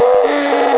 zm_injure.wav